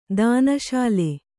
♪ dāna śale